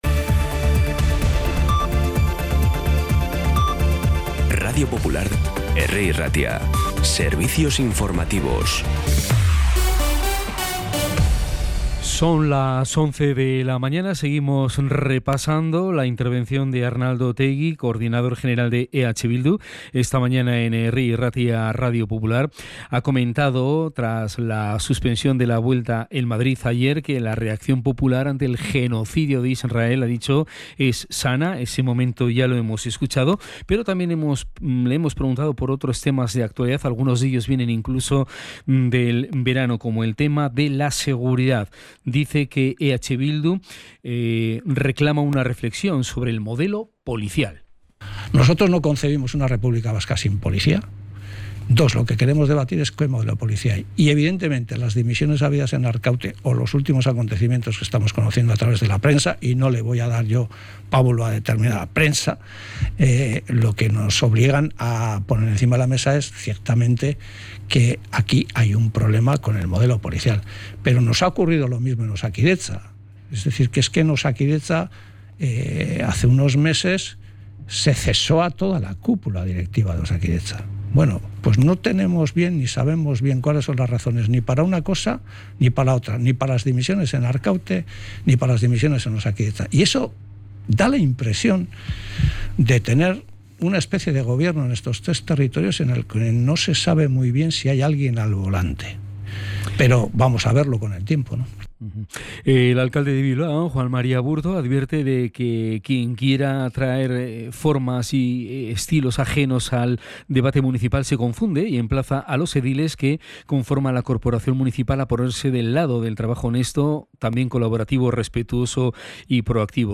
Las noticias de Bilbao y Bizkaia del 15 de septiembre a las 11
Los titulares actualizados con las voces del día. Bilbao, Bizkaia, comarcas, política, sociedad, cultura, sucesos, información de servicio público.